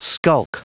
Prononciation du mot skulk en anglais (fichier audio)
Prononciation du mot : skulk